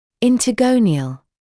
[ˌɪntə’gəunɪəl][ˌинтэ’гоуниэл]межгониальный